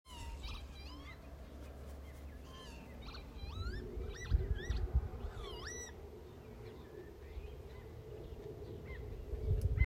Artig sang - hvilken fugl?
Den fløy rundt blant måkene og lagde denne lyden, og jeg syns også jeg hørte flapping av vingeslagene, men usikker på om det kom fra den.
Dette er opptak av fluktspill av vipe.
fuglelyd.m4a